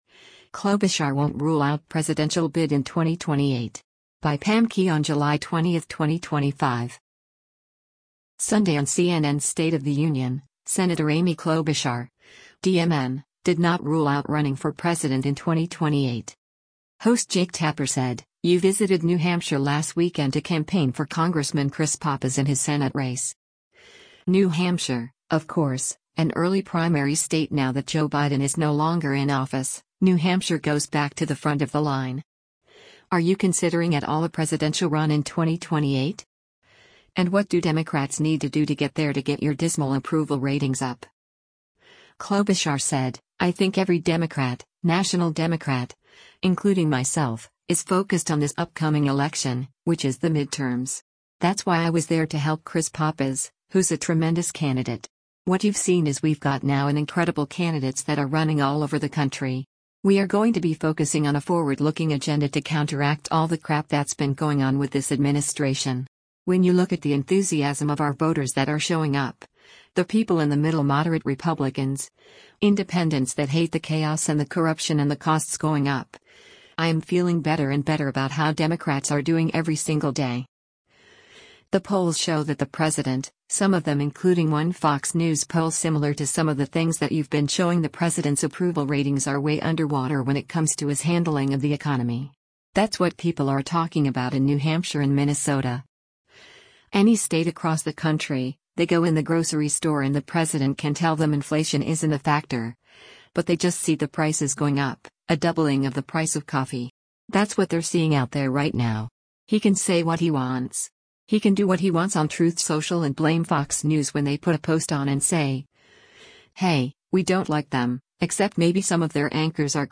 Sunday on CNN’s “State of the Union,” Sen. Amy Klobuchar (D-MN) did not rule out running for president in 2028.